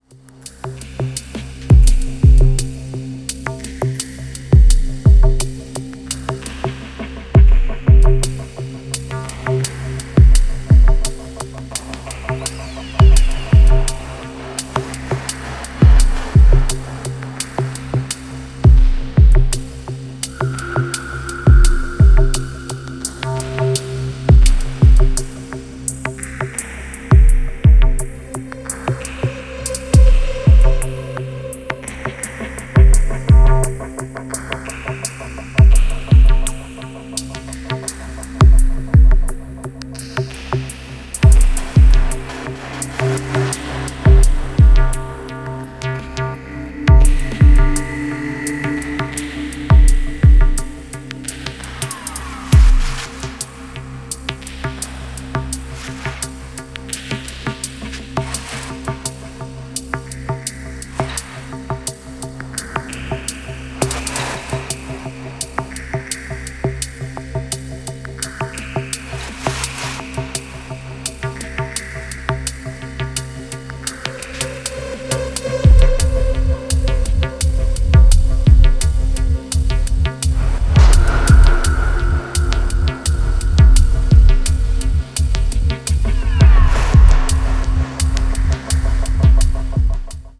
非常に先進的、かつ神聖さすら感じさせるDNB表現を堂々開陳